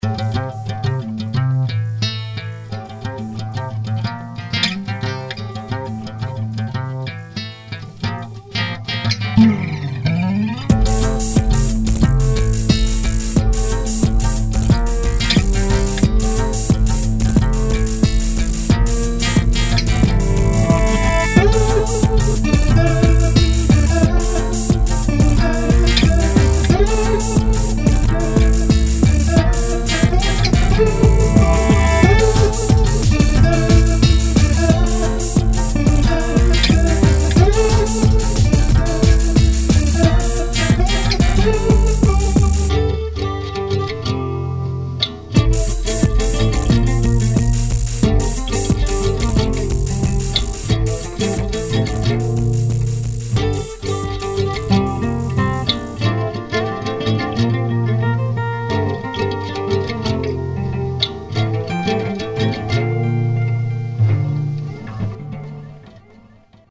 Кое-что акустическое. (1-ая демка)